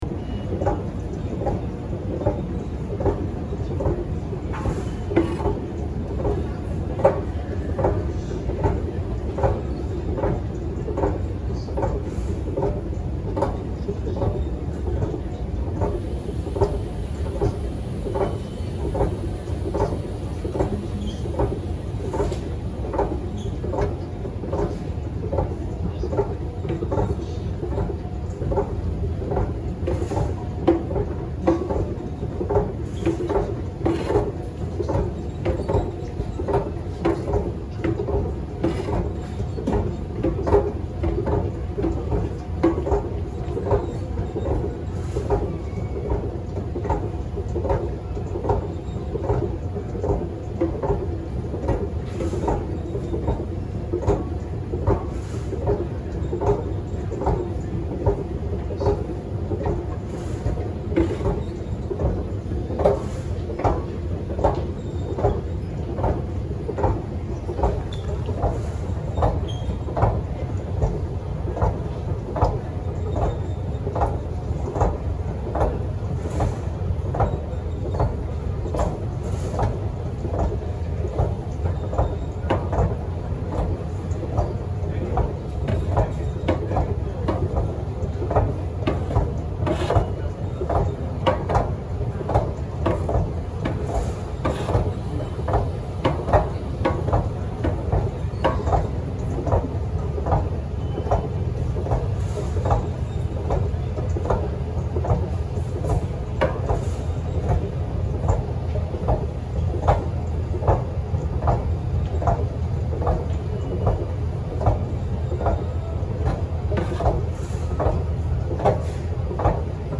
Звуки эскалатора в метро и в торговом центре, подъём и спуск, шум движущихся ступенек mp3 для монтажа видео.
5. Характерное движение ступеней и непрерывный механический шум (в торговом центре)